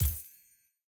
sfx-exalted-shop-button-click.ogg